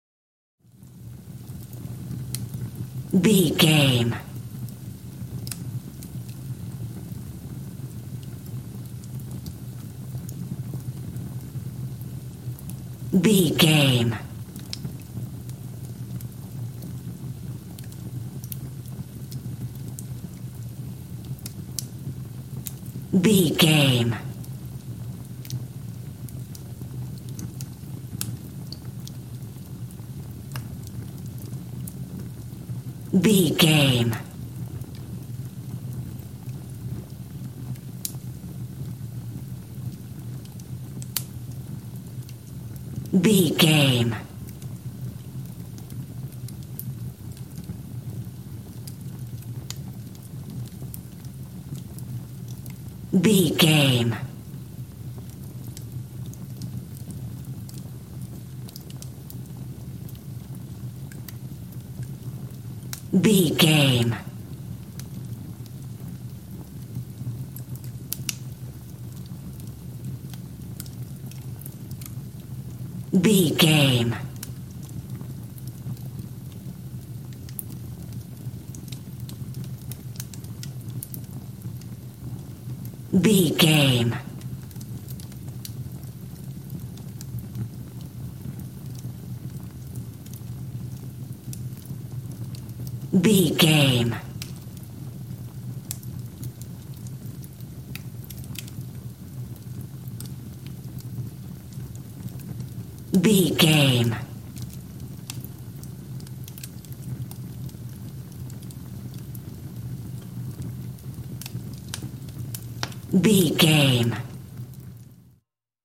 Fire with crakle
Sound Effects
torch
fireplace